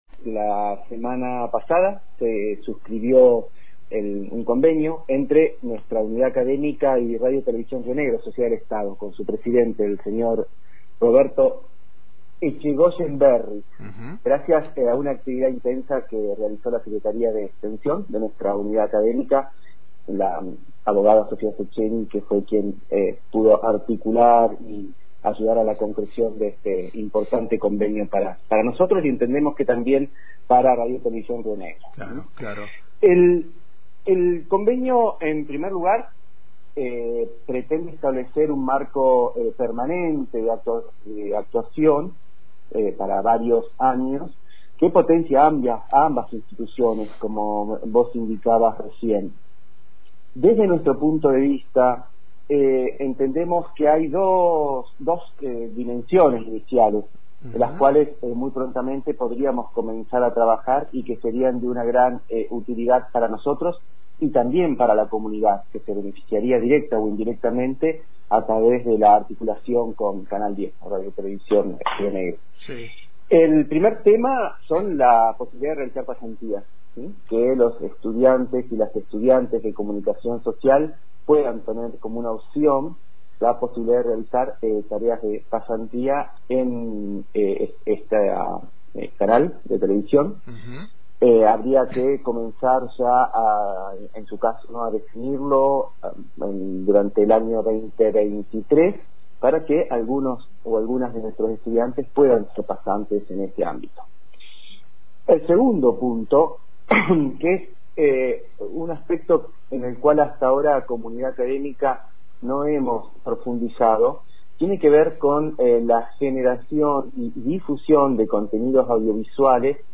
Este es el primer convenio que se firma con el medio estatal de comunicación, el que ratifica la agenda de trabajo conjunta a desarrollar durante el 2023. Escuchá la entrevista completa